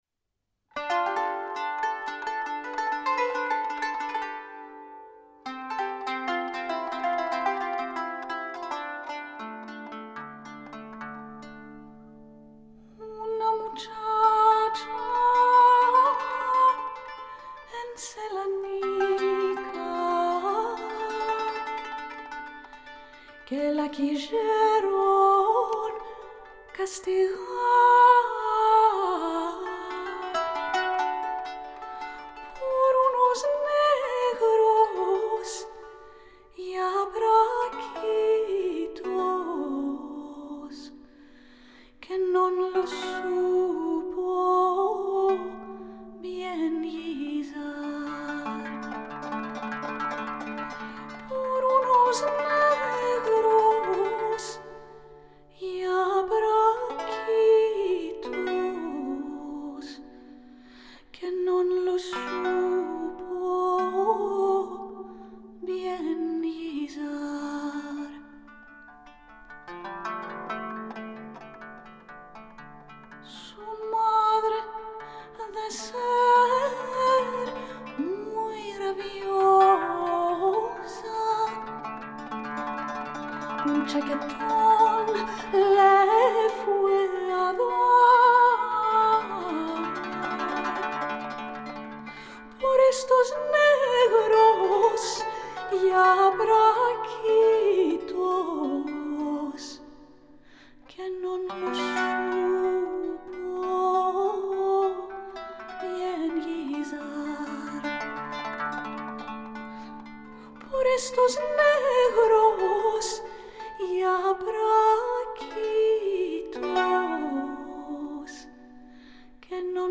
Жанр: Alternatif.